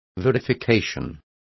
Complete with pronunciation of the translation of verification.